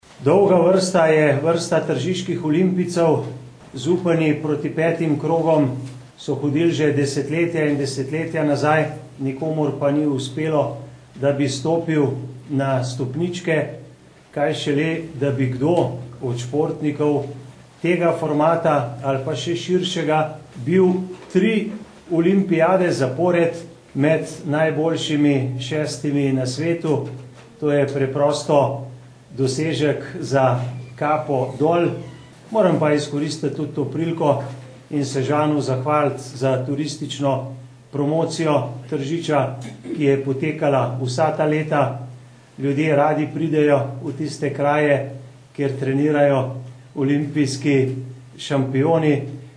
izjava_zupanobcinetrzicmag.borutsajovicobsprejemuzanakosirja.mp3 (1,2MB)